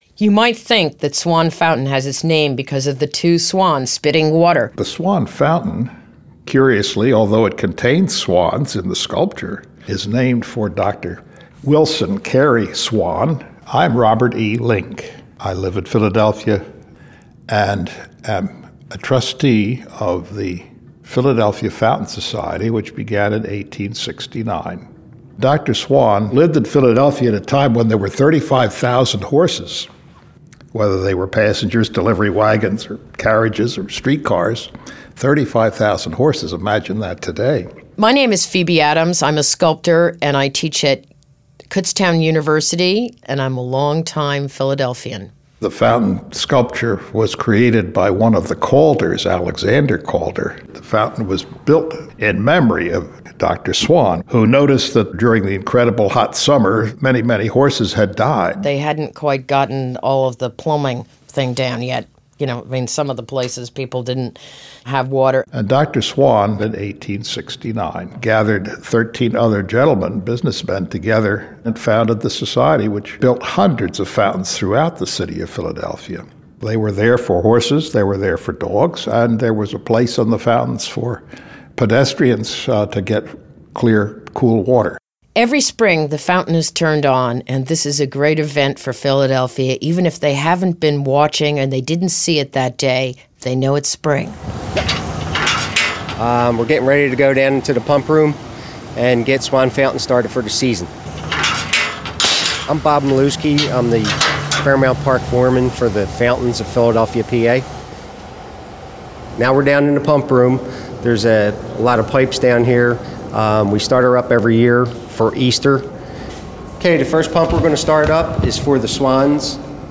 Available for free by phone, mobile app, or online, the program features more than 150 voices from all walks of life – artists, educators, civic leaders, historians, and those with personal connections to the artworks.